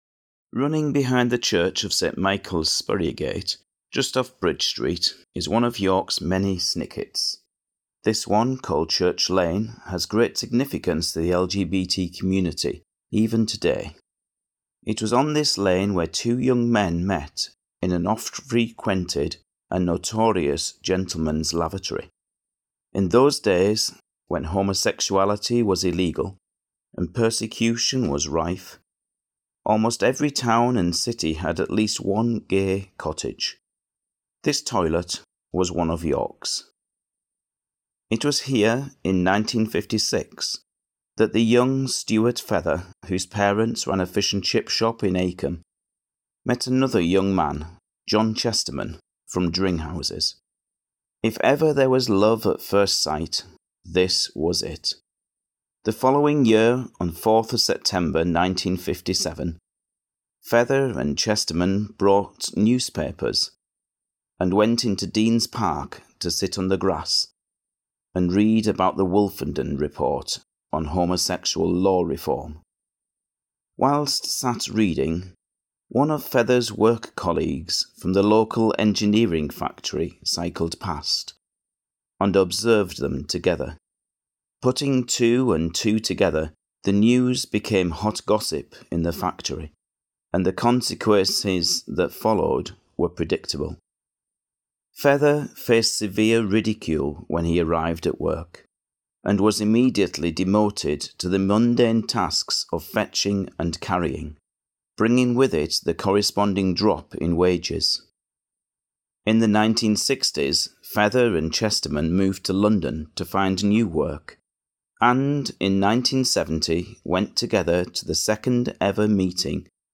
The tour is narrated by older LGBT residents, drawing on their personal insights, experiences and memories throughout a period of unprecedented social and legislative change within the UK.